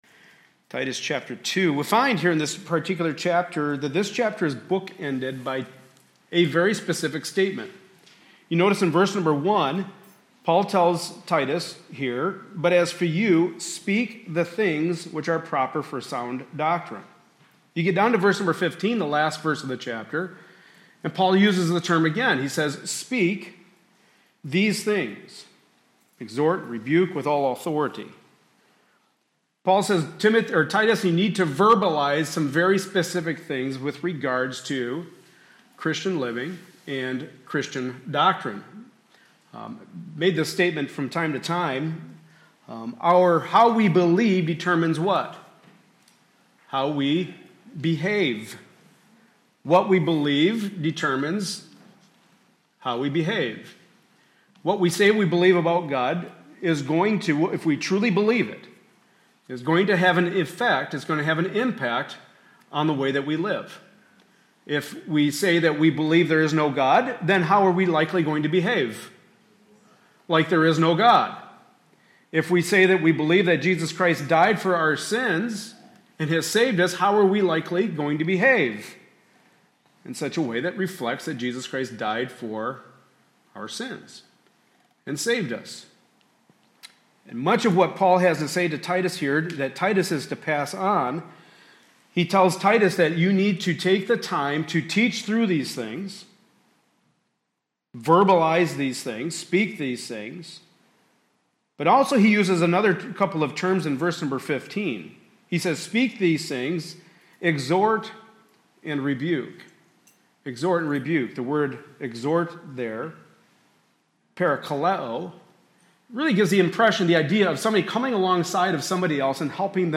Passage: Titus 2:11-15 Service Type: Sunday Morning Service